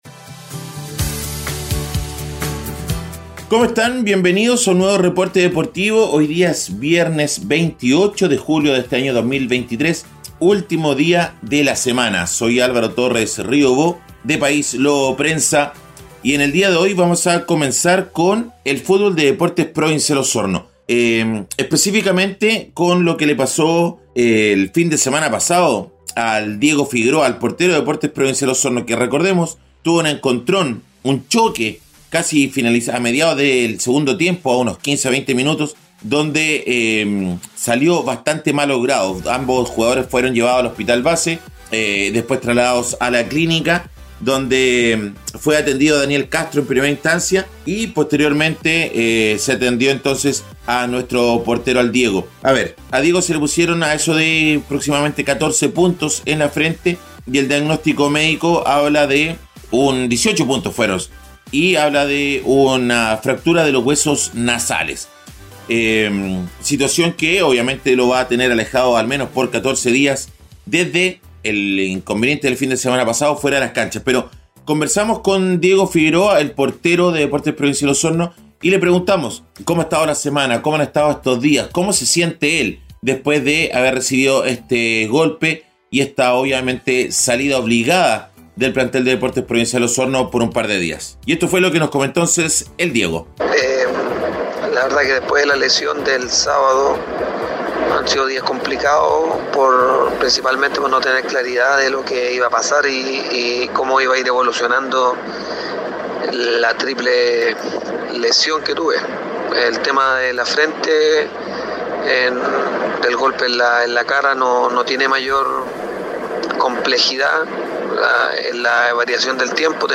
Reporte Deportivo 🎙 Podcast 28 de julio de 2023